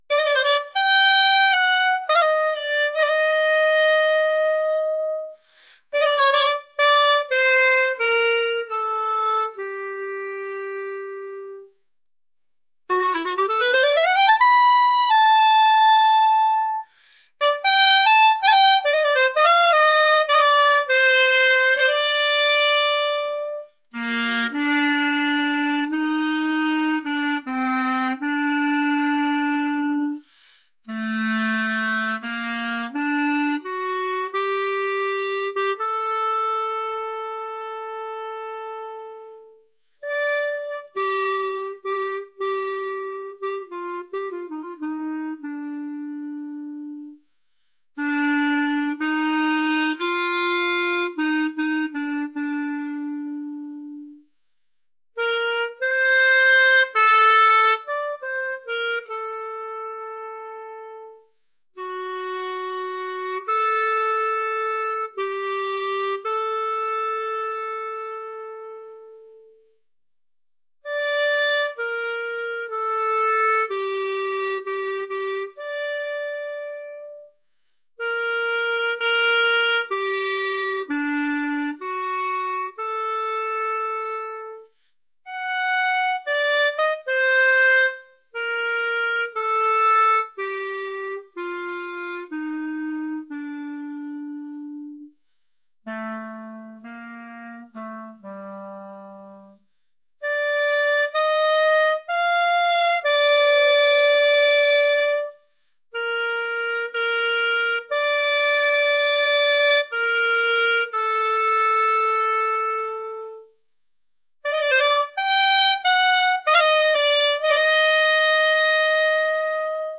traditional | lively